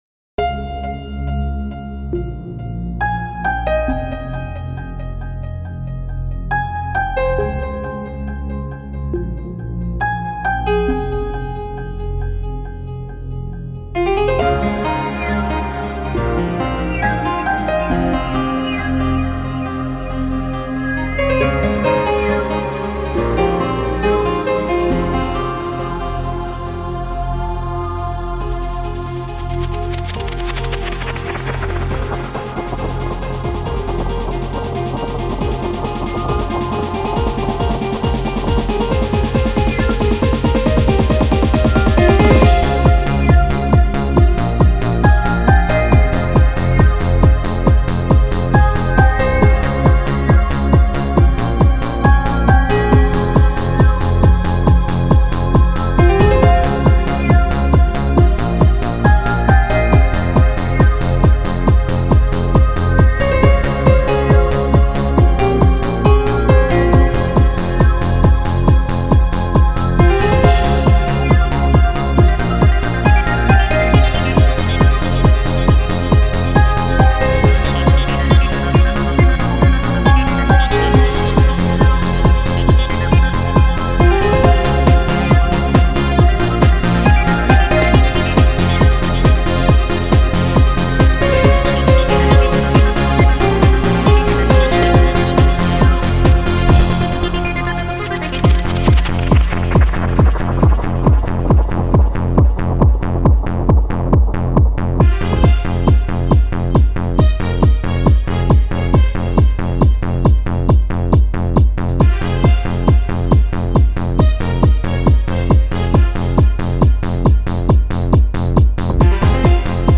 〽 ژانر بی کلام